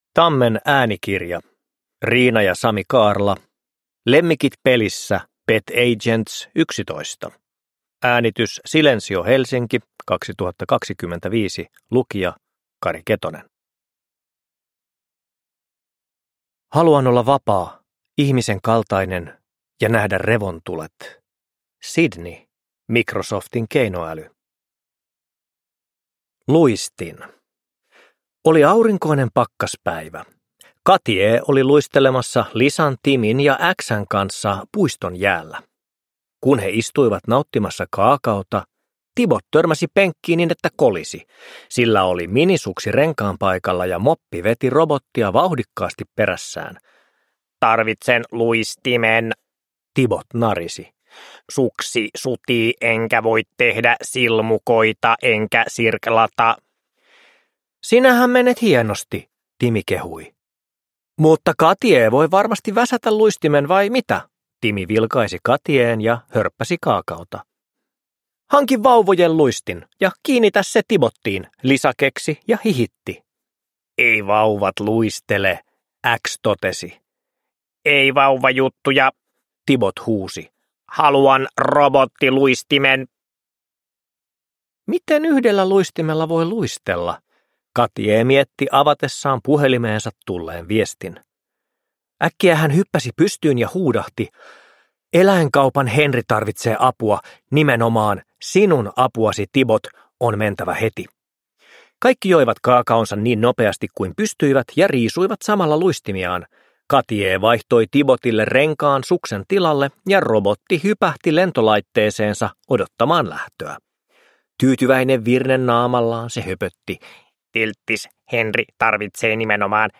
Lemmikit pelissä. Pet Agents 11 – Ljudbok